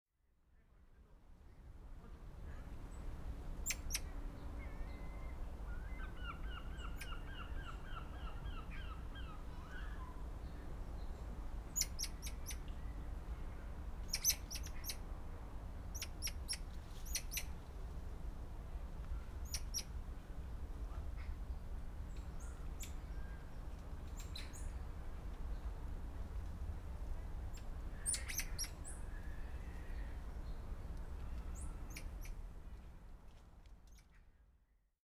Squirrel monkeys London Zoo
Tags: Sound Map in London London sounds UK Sounds in London London